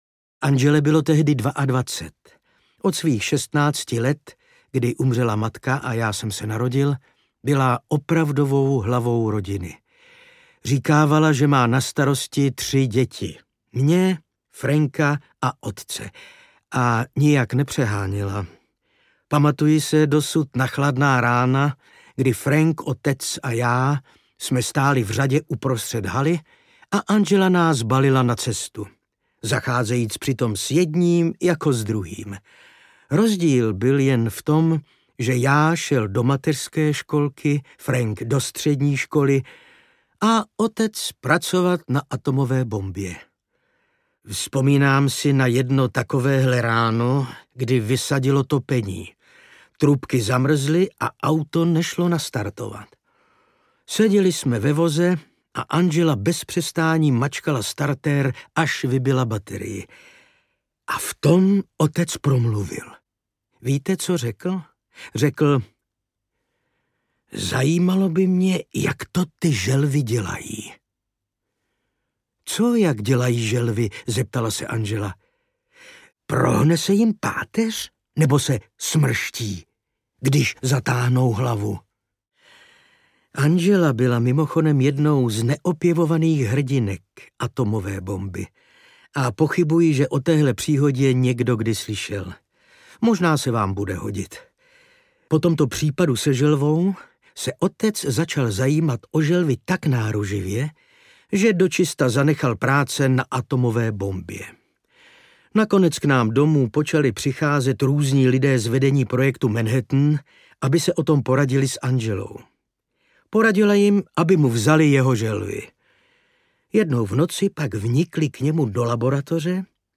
Kolíbka audiokniha
Ukázka z knihy
• InterpretIvan Řezáč